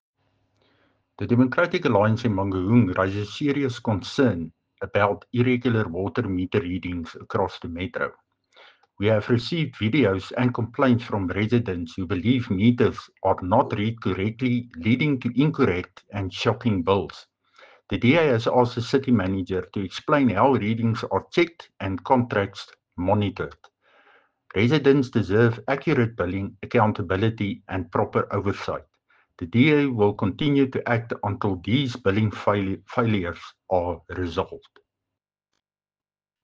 Afrikaans soundbites by Cllr Dirk Kotze and